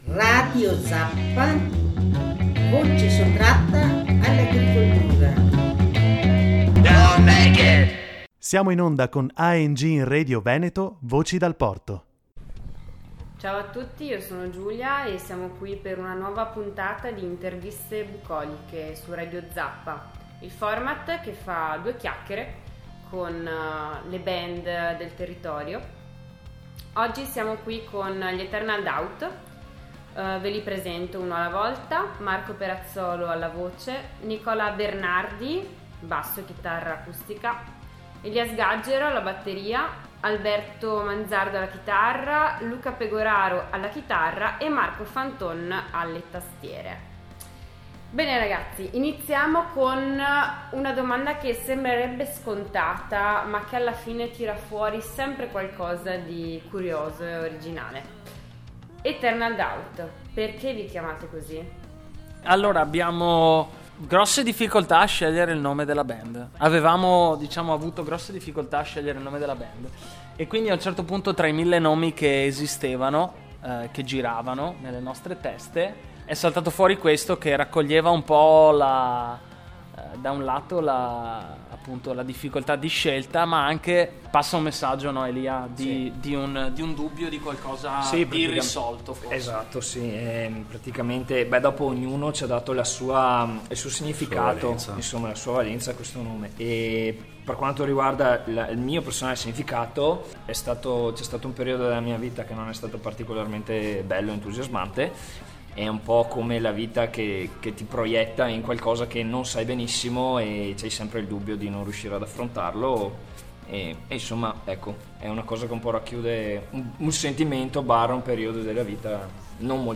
lead vocals
lead guitar
rhytmic guitar
keyboards
bass guitar
drums